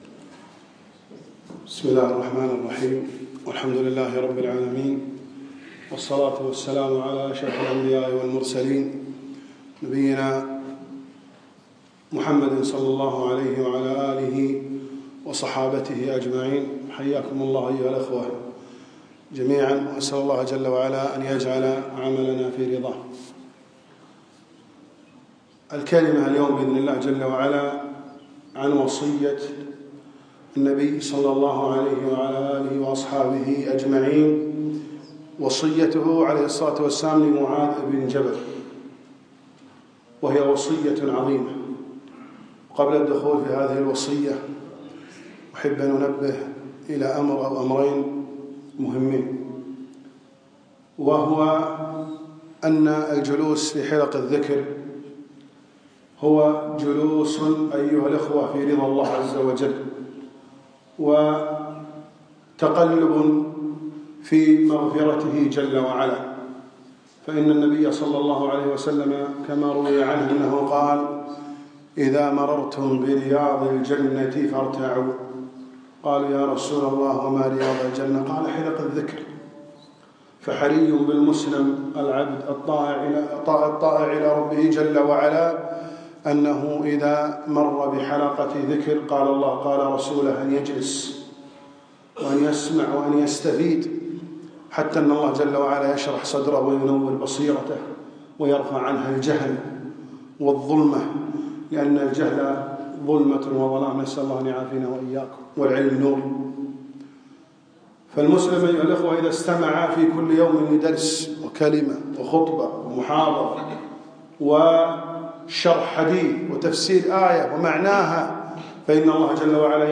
يوم الجمعة 12 رجب 1436 الموافق 1 5 2015 بمسجد سعد سلطان السالم